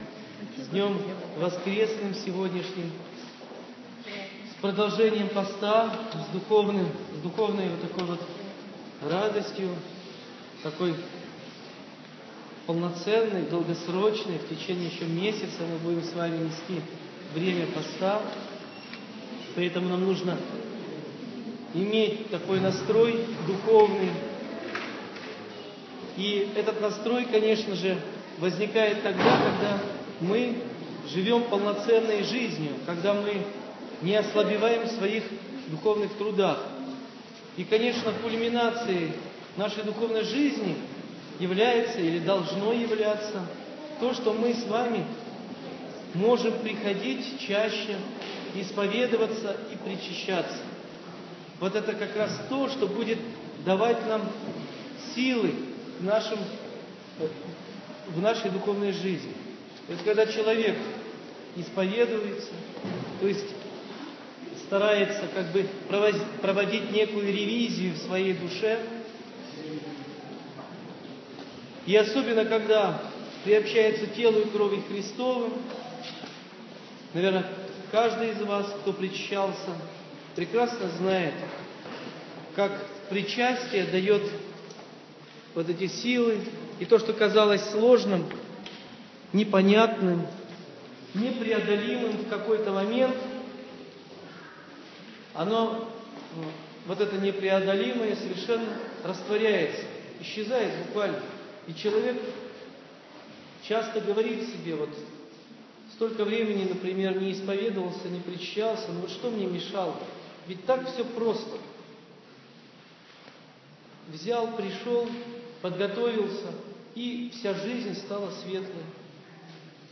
27.03.16 Слово настоятеля после Богослужения